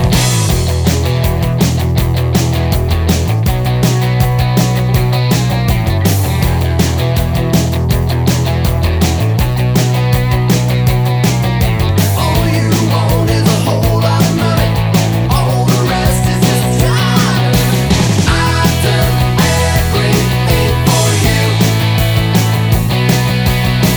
Soft Rock